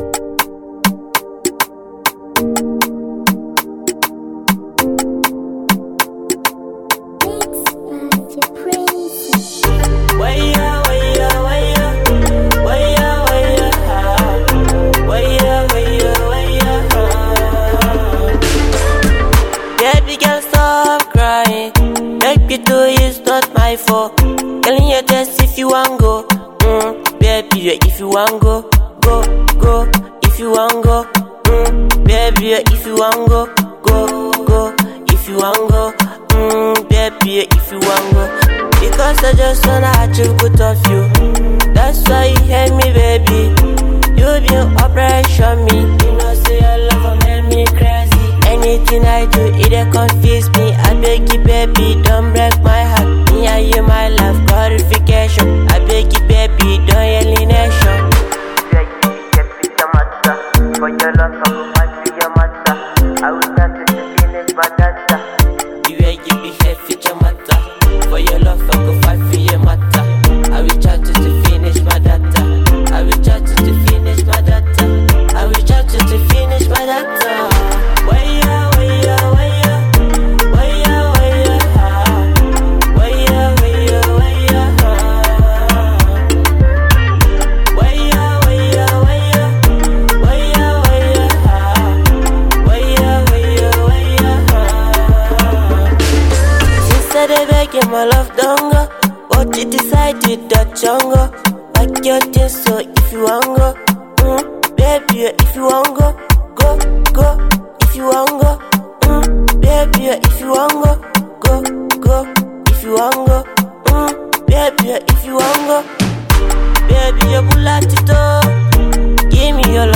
Popular afro singer